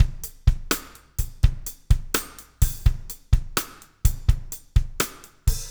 BLUE STICK-L.wav